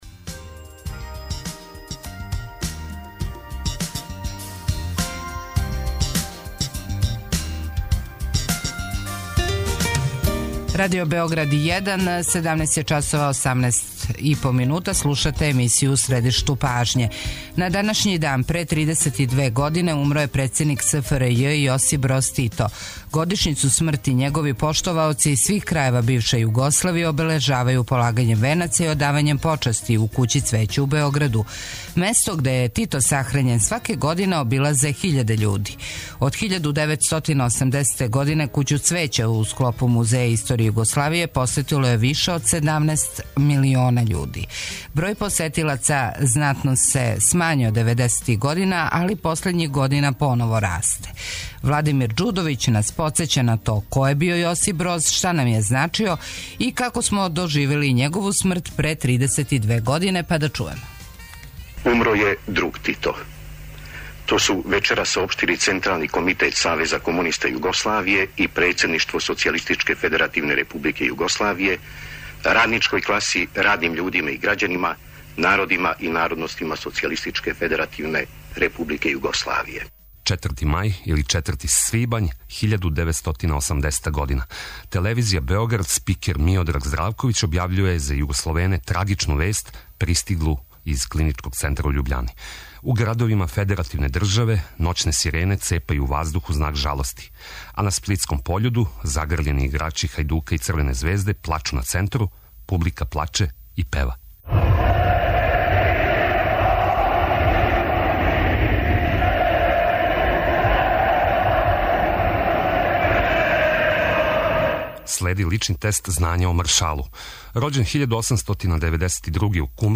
доноси интервју